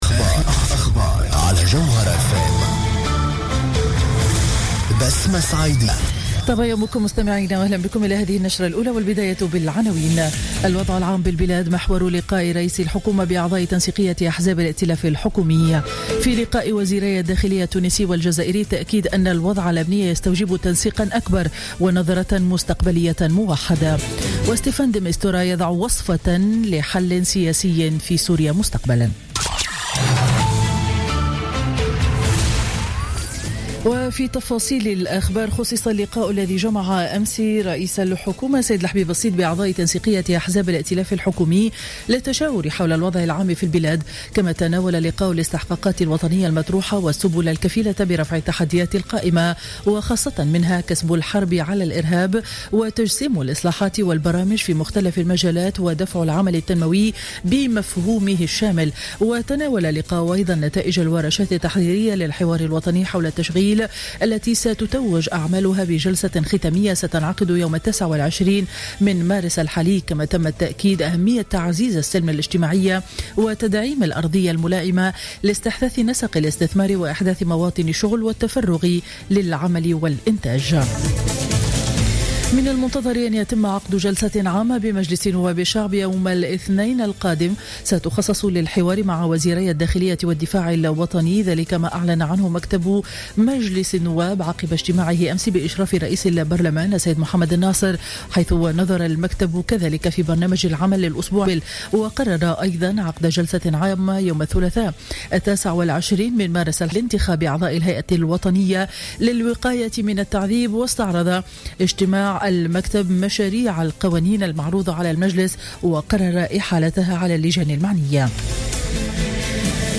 نشرة أخبار السابعة صباحا ليوم الجمعة 25 مارس 2016